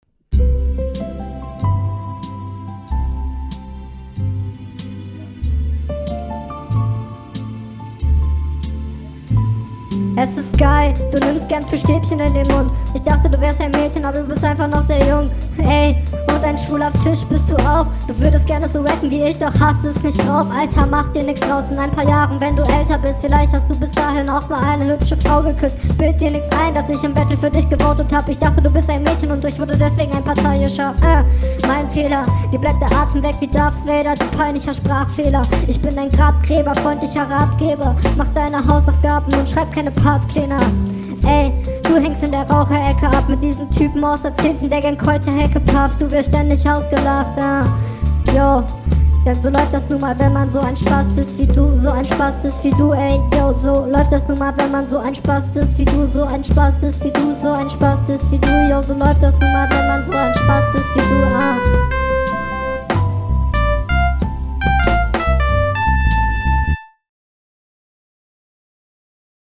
Wenn es schneller wird ist es mir etwas zu anstrengend.